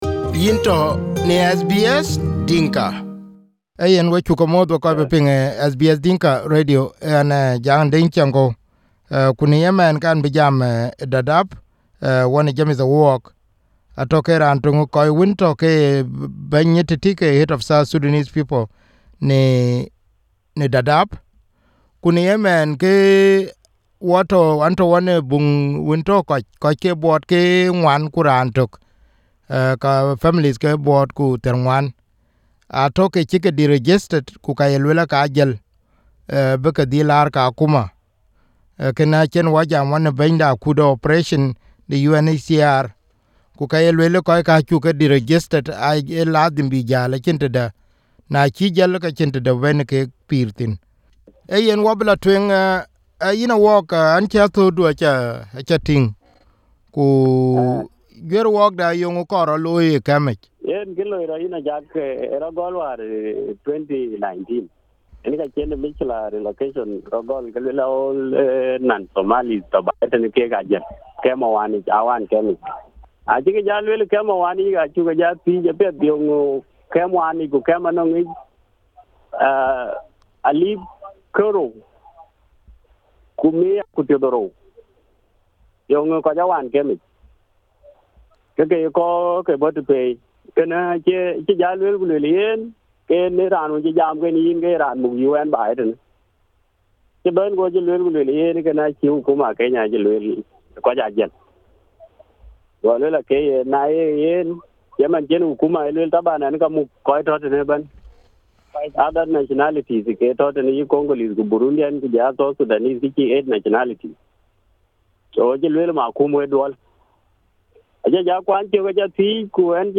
SBS Dinka View Podcast Series